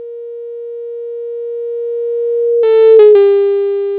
Velocity of object: 25m/s
Sound generated by source: 440Hz tone
This method should have worked, but for some reason the pitch does not shift properly.